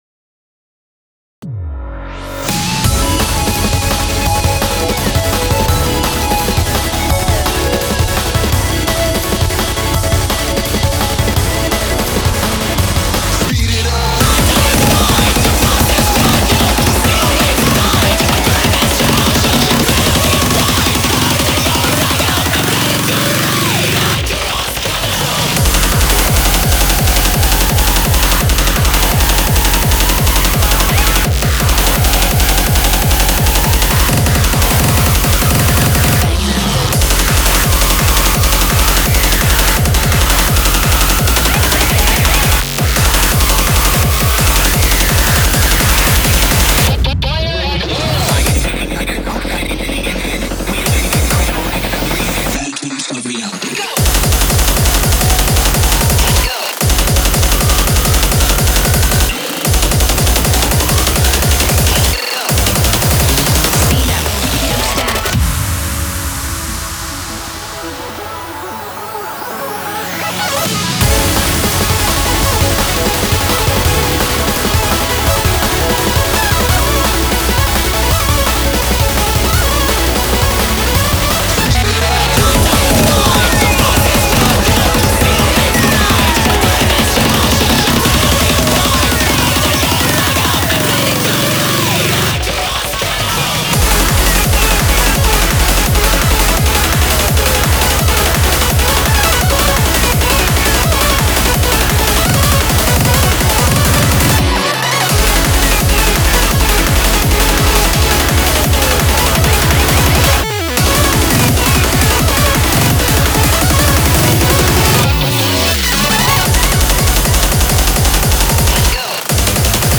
BPM169
Audio QualityPerfect (High Quality)
2020-08-22: Slightly louder audio; no changes to the steps